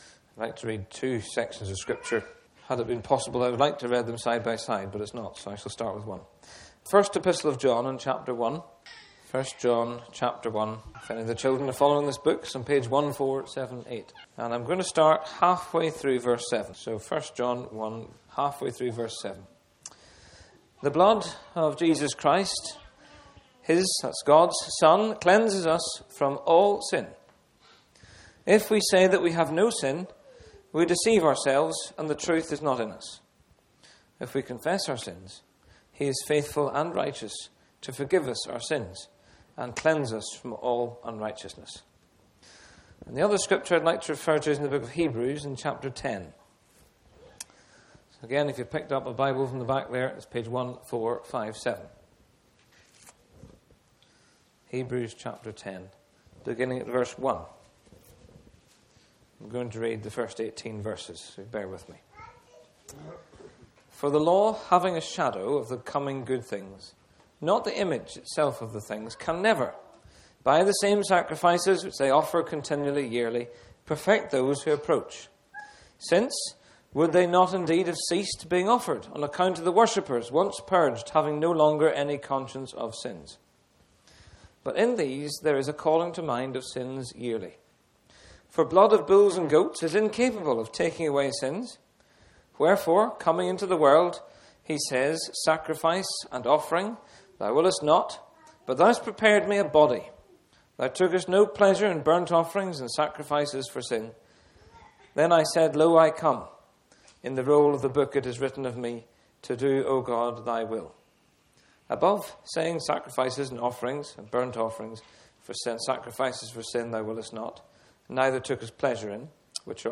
This preaching explores the only way to find real forgiveness and peace with God—not through our efforts, but through the finished work of Jesus Christ. This Gospel preaching points to the cross, where Christ bore our sins, offering cleansing, freedom, and a new beginning to all who believe.